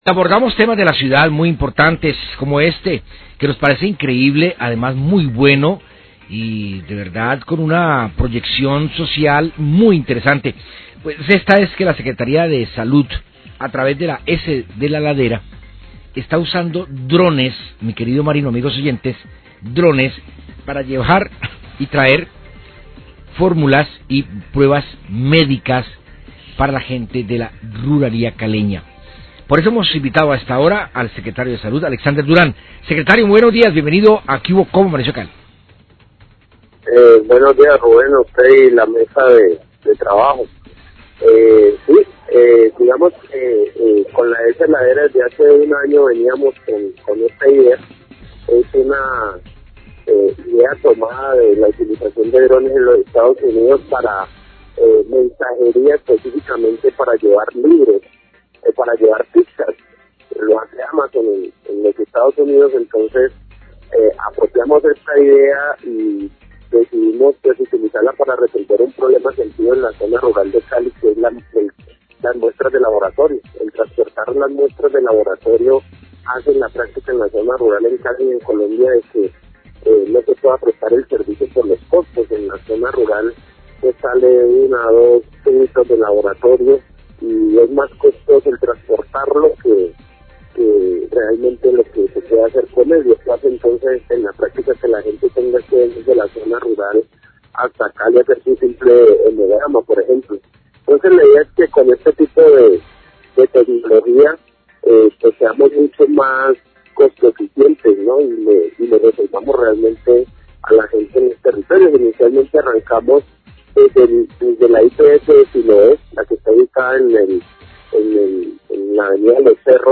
Radio
La secretaría de Salud a través de la ESE de la ladera, está usando Drones para llevar y traer formulas médicas para la gente de la zona rural de la ciudad. El secretario de Salud de Cali, Alexander Duran, habla acerca de este tema.